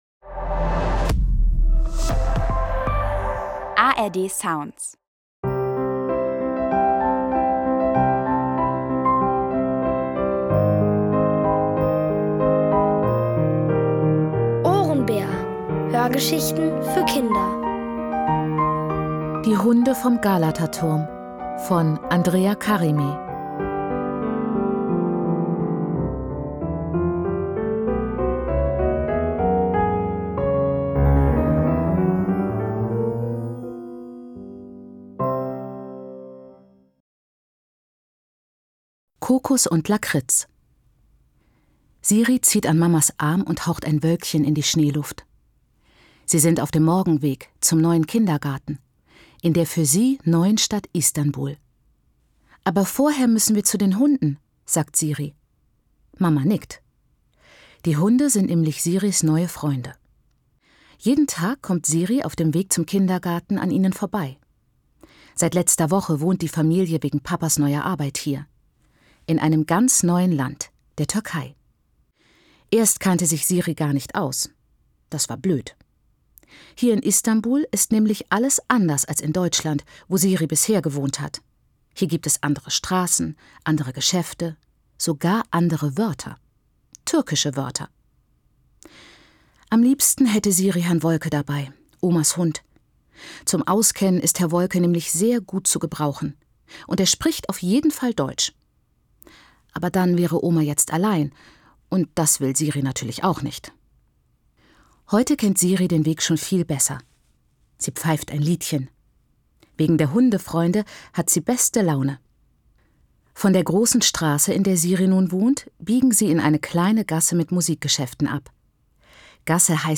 Die Hunde vom Galataturm | Die komplette Hörgeschichte!